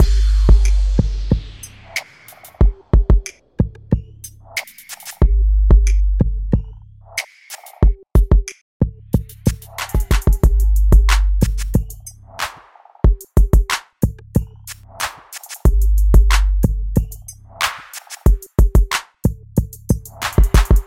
嘻哈俱乐部节拍鼓循环
描述：俱乐部风格的HipHop节奏的快节奏鼓循环。酷炫的节奏，带有混响的拍子，然后是808的拍子，2个不同的拍子，2个不同的拍子，2个不同的踢腿，一个踢腿是G调，另一个踢腿是D调，还有一个G调的低音踢腿，三角铁和其他打击乐器贯穿其中。
Tag: 92 bpm Hip Hop Loops Drum Loops 3.51 MB wav Key : G